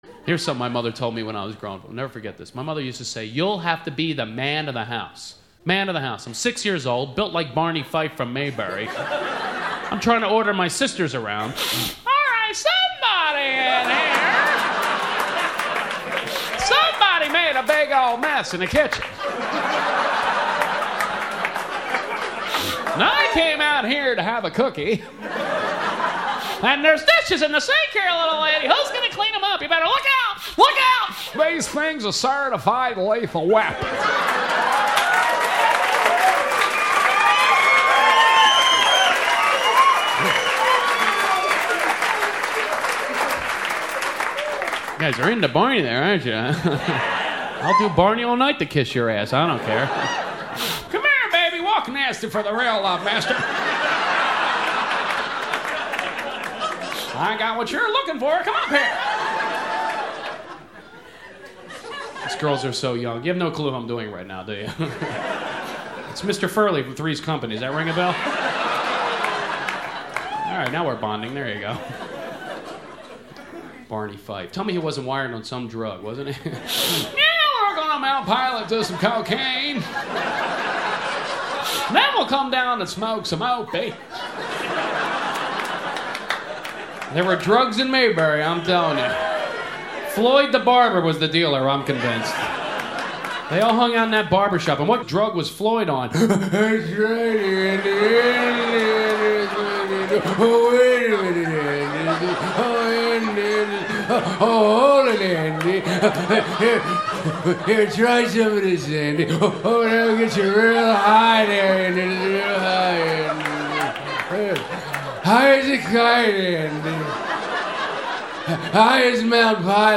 Tags: Comedy